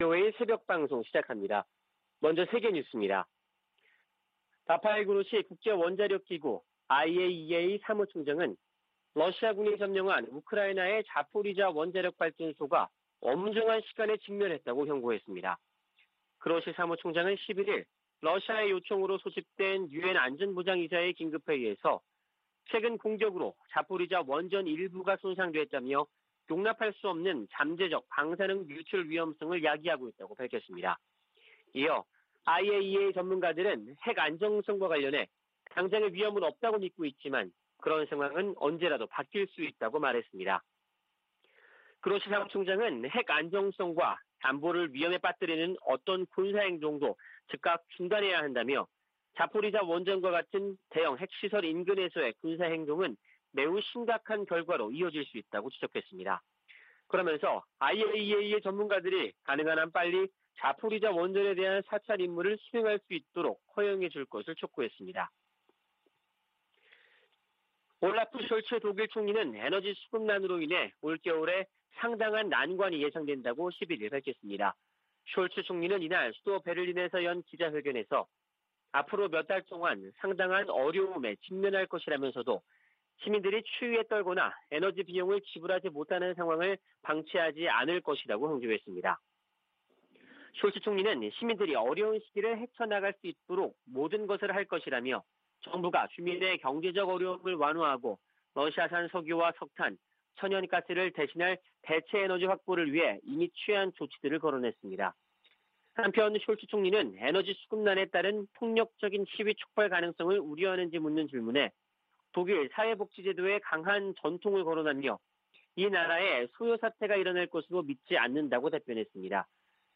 VOA 한국어 '출발 뉴스 쇼', 2022년 8월 13일 방송입니다. 방한한 안토니우 구테흐스 유엔 사무총장은 북한의 완전하고 검증 가능하며 불가역적인 비핵화를 전적으로 지지한다고 밝혔습니다. 윤석열 한국 대통령은 에드 마키 미 상원 동아태소위원장을 접견하고 동맹 강화와 한일 경제 협력에 애써 준 데 대해 감사의 뜻을 전했습니다. 미 국무부는 ‘코로나 방역전 승리’를 주장한 북한 당국이 국제사회 백신 지원 제안을 수용해야 한다고 강조했습니다.